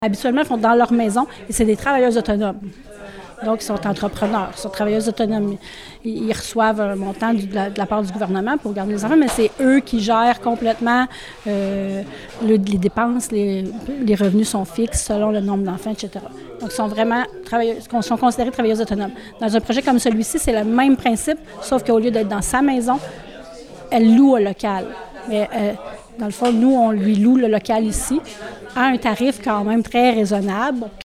Comme l’a expliqué la mairesse de Fortierville, madame Julie Pressé, les éducatrices sont des travailleuses autonomes.